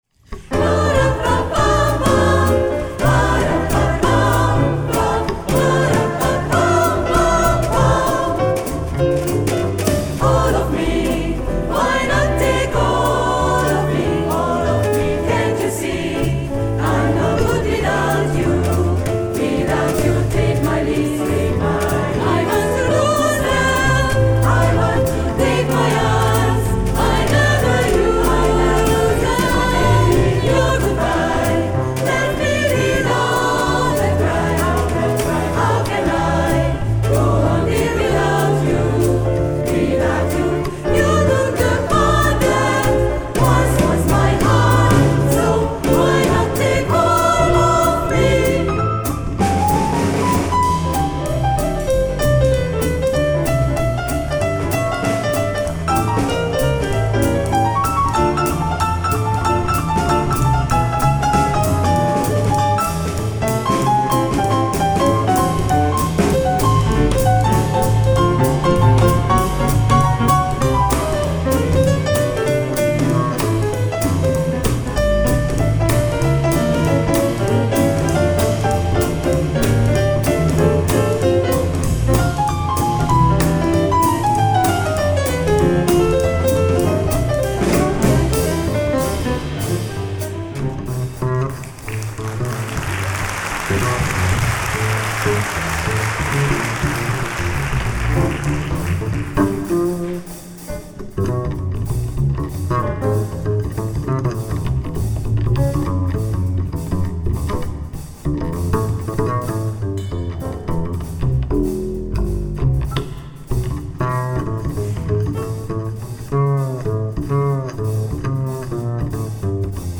Cantissimo Jazz
Pár hónapon belül együttesünk 14 főre bővült, s a heti rendszeres próbáknak köszönhetően egy kis kamara-vegyeskarrá fejlődtünk.